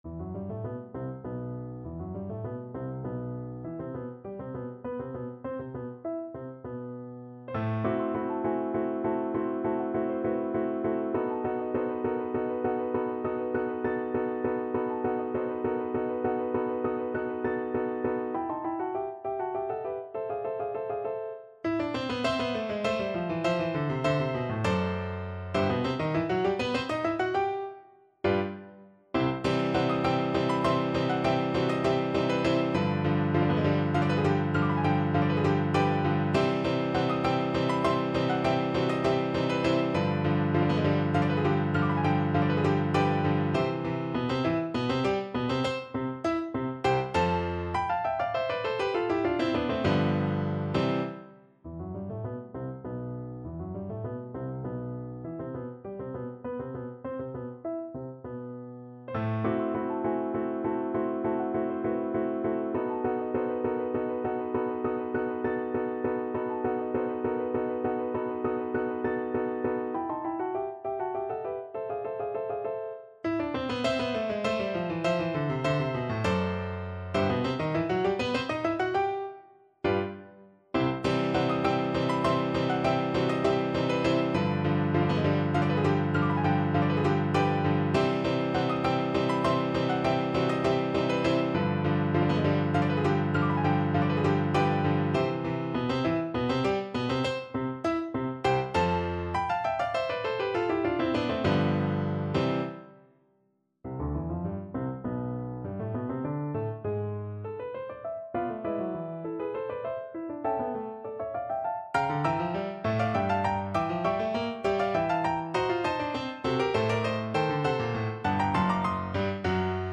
No parts available for this pieces as it is for solo piano.
MENUETTO Allegro =200 (View more music marked Allegro)
3/4 (View more 3/4 Music)
Piano  (View more Intermediate Piano Music)
Classical (View more Classical Piano Music)